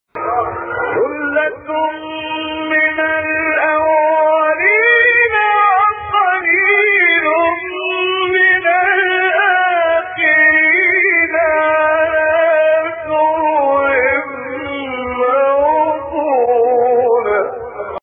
گروه فعالیت‌های قرآنی: مقاطع زیبا و شنیدی از قاریان مصری و ایرانی که در کانال‌ها و گروه‌های قرآنی تلگرام منتشر شده است، ارائه می‌شود.
قطعه‌ای بسیار زیبا از تلاوت سوره واقعه با صدای شیخ محمد عمران